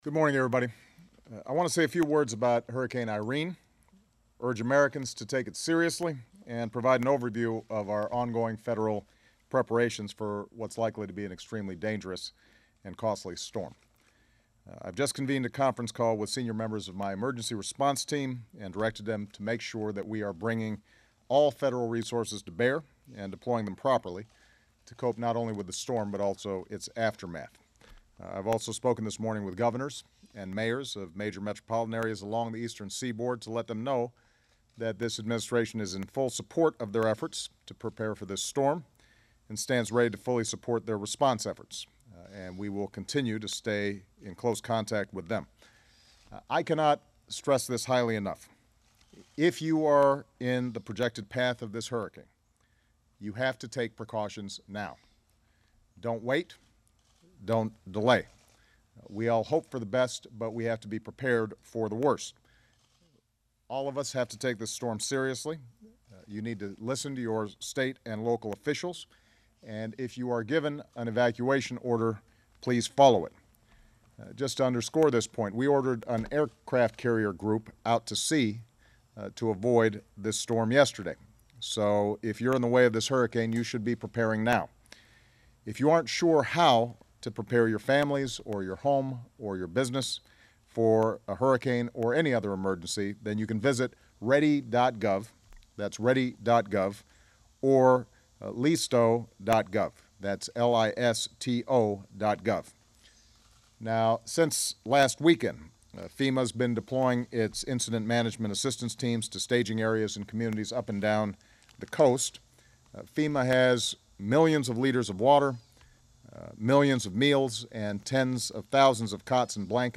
U.S. President Barack Obama speaks about Hurricane Irene
President Barack Obama urges Americans in the path of Hurricane Irene to take precautions and provides an update on ongoing federal preparations. He makes his comments while on Martha's Vineyard, MA.
Recorded in Martha's Vineyard, Mass., Aug. 26, 2011.